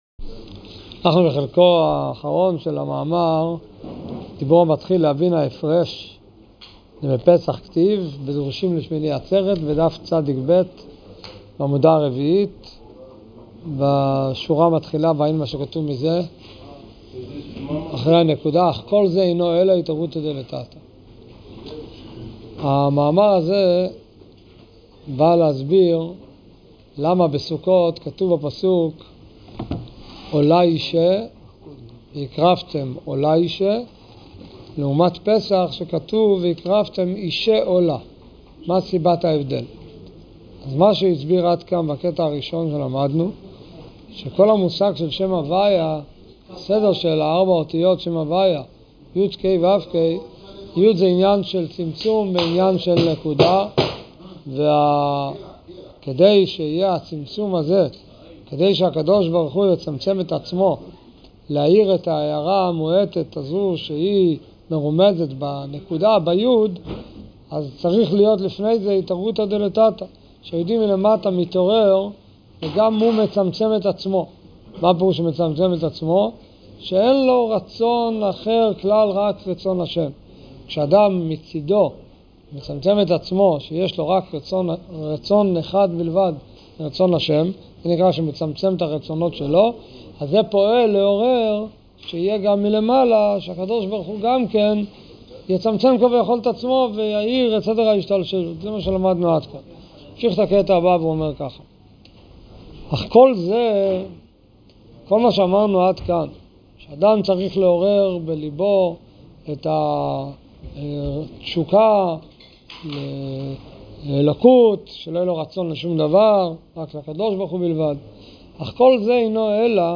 שיעורים בתורה אור: